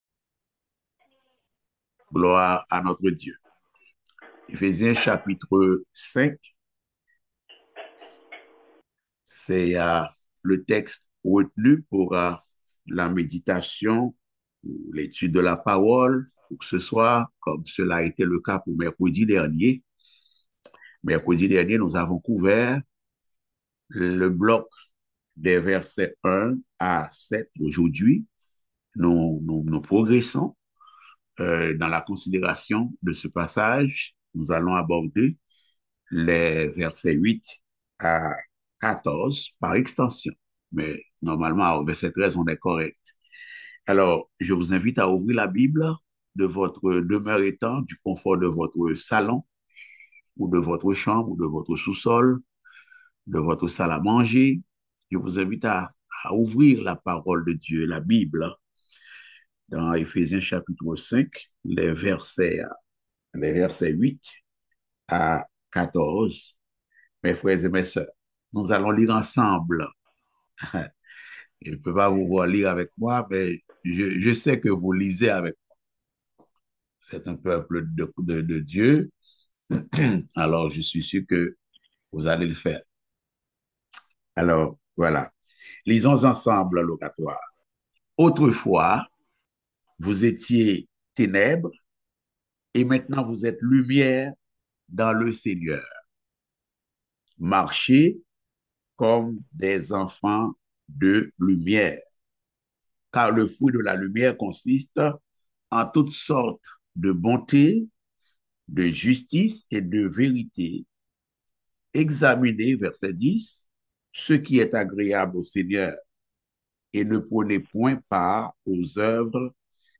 Passage: Éphésiens 5.8-14 Type De Service: Études Bibliques « Avancez sans crainte car Dieu est avec vous.